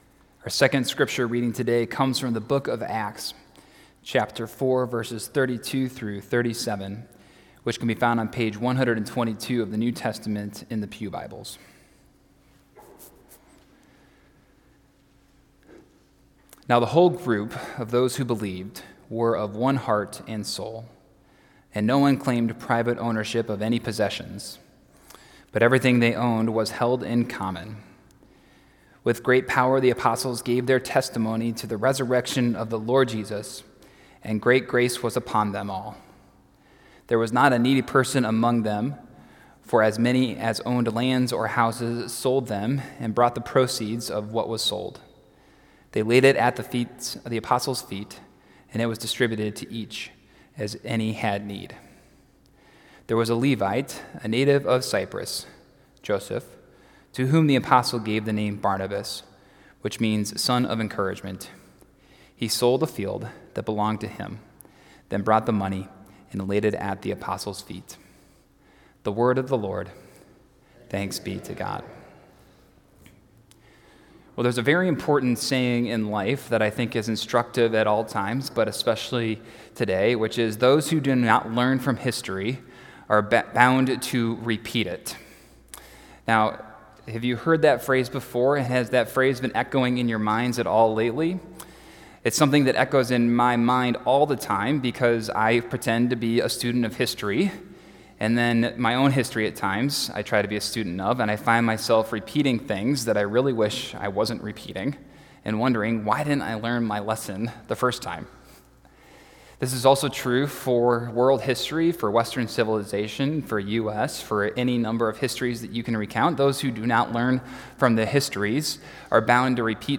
2018-10-21-Sermon-Final.mp3